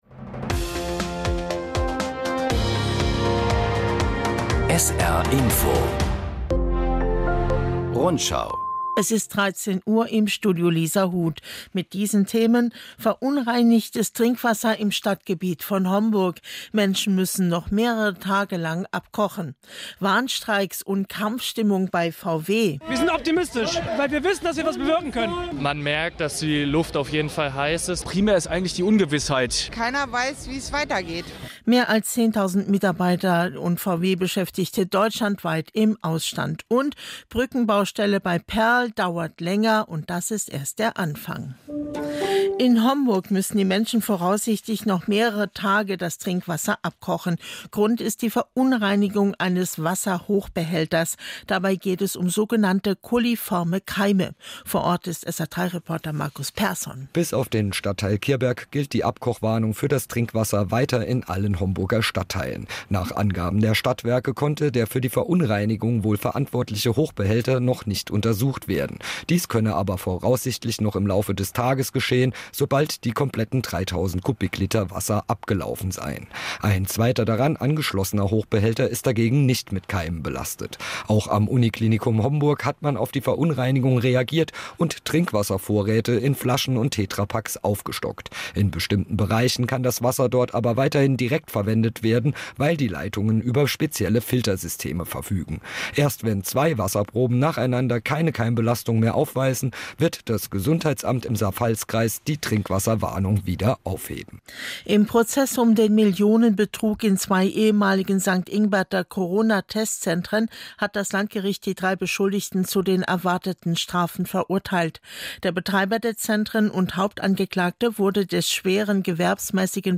… continue reading 7 episodios # Nachrichten